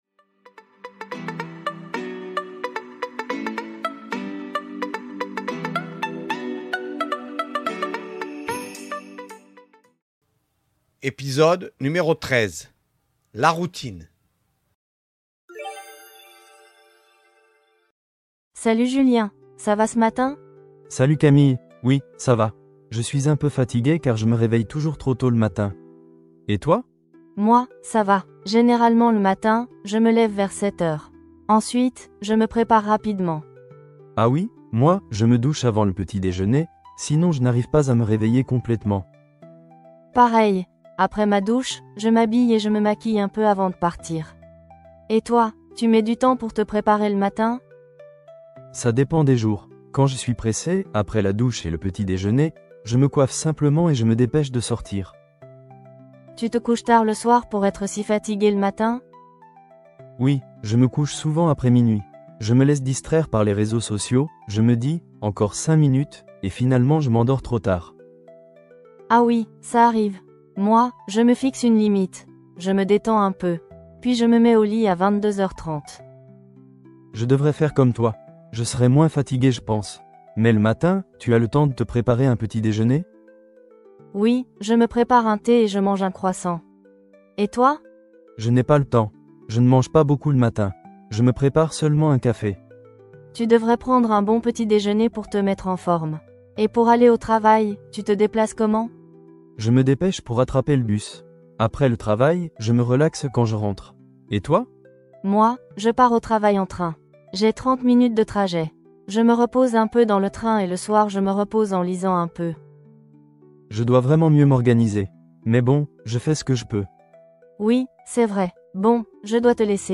Voici un dialogue pour les élèves de niveau débutant. Avec cet épisode, vous verrez le vocabulaire de la routine et les verbes pronominaux.
013-Podcast-dialogues-La-routine.mp3